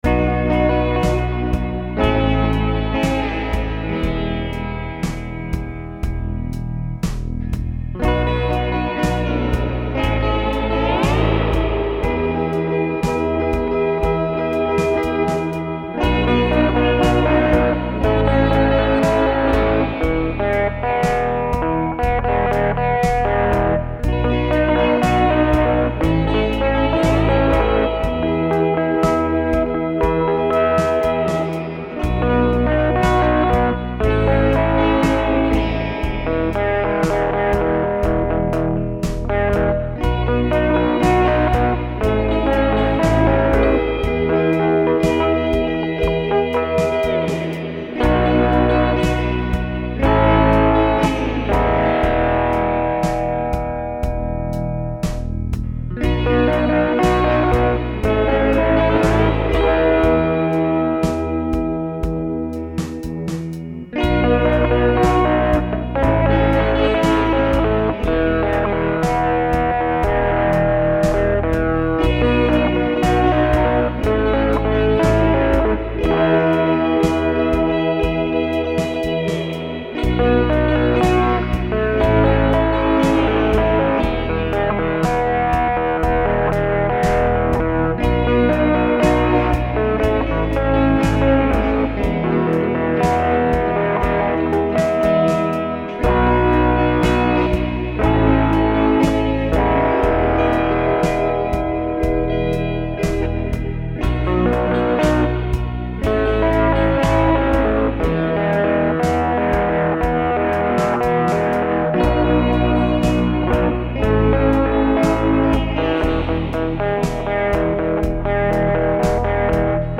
Later in the evening, I stepped back into the studio and made another pass at a recording. In particular, I wanted to get a slide guitar into the mix, so I tuned my electric to an open tuning and picked up the slide and started to wail.
open_duet1.mp3